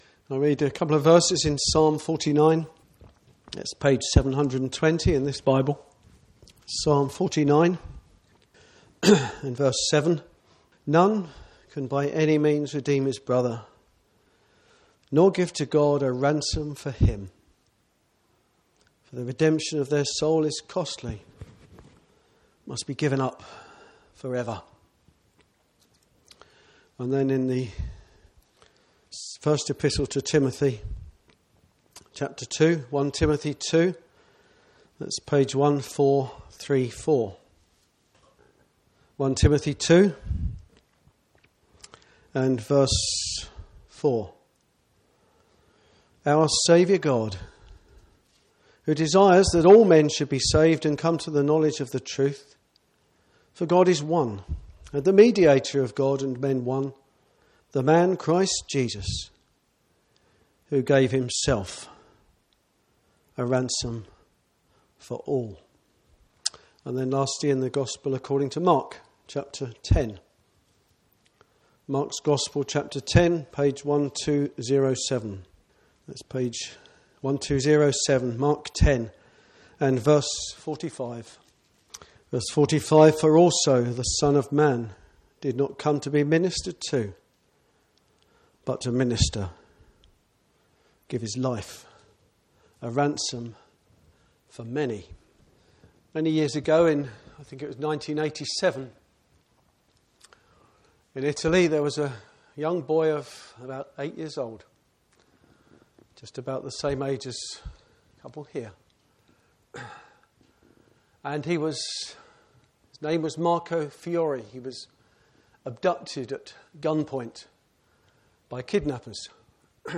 Gospel Preachings